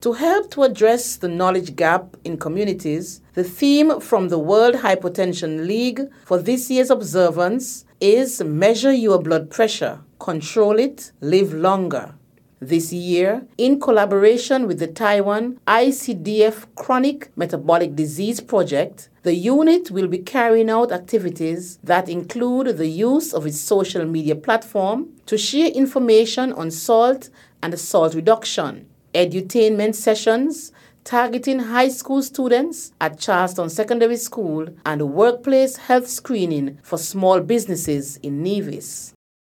Junior Minister of Health on Nevis, the Hon. Hazel Brandy-Williams.